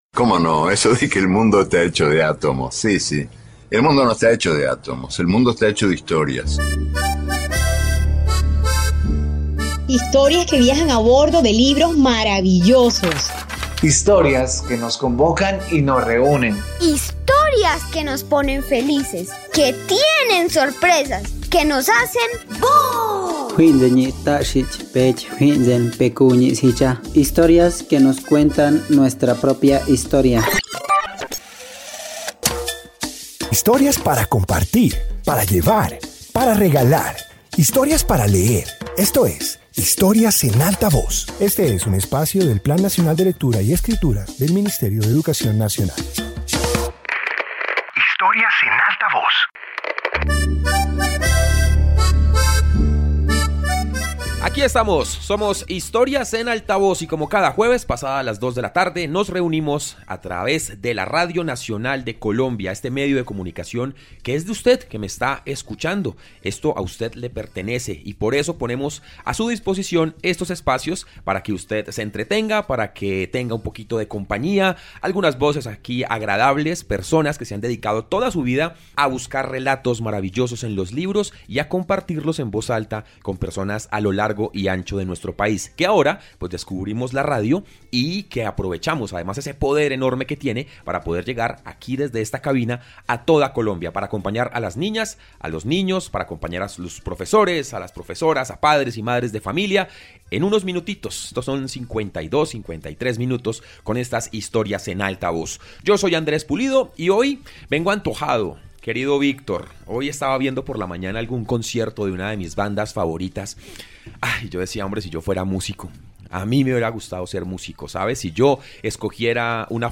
Introducción Este episodio de radio reúne historias donde la música, la pintura y otras expresiones artísticas aparecen dentro de los relatos. Presenta escenas y descripciones que muestran el diálogo entre arte y literatura.